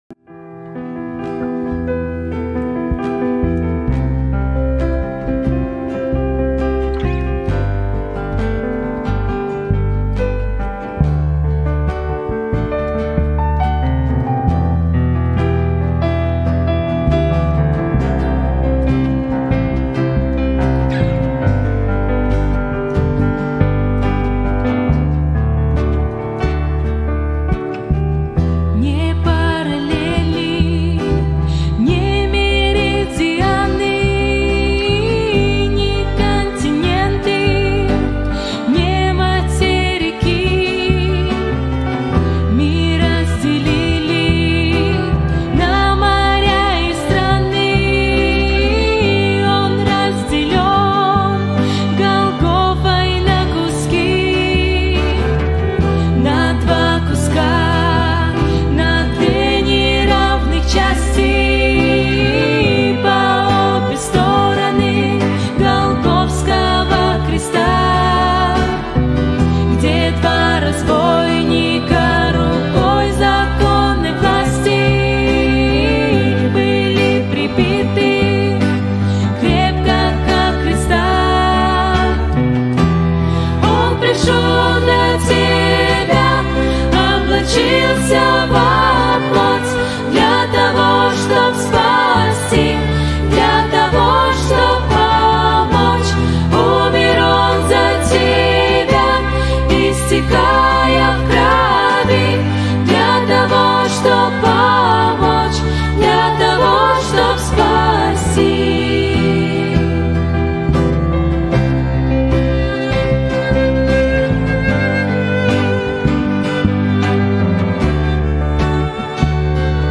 Хор